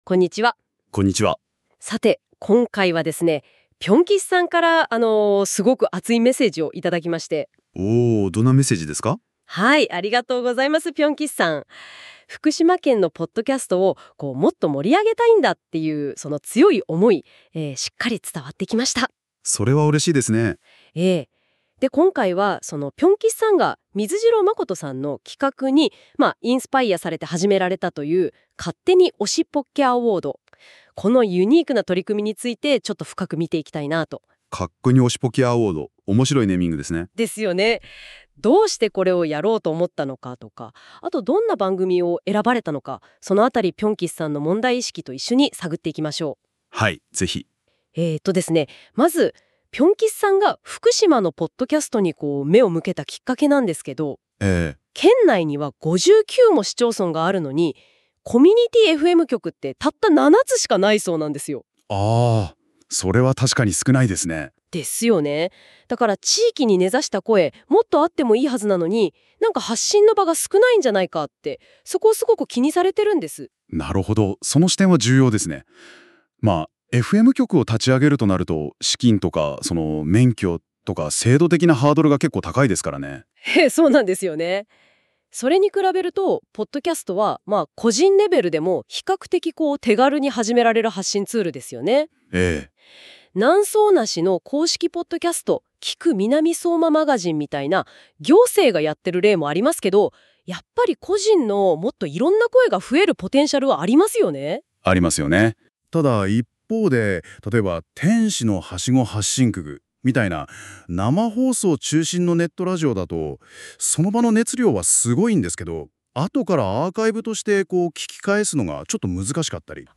「福島野遊」を「ふくしまのゆう」とか誤読しています。
Audio Channels: 2 (stereo)